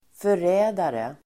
Ladda ner uttalet
förrädare substantiv, traitor Uttal: [för'ä:dare] Böjningar: förrädaren, förrädare, förrädarna Definition: person som förråder någon; angivare Avledningar: förräderi (treachery, betrayal) Sammansättningar: landsförrädare (traitor to one's country)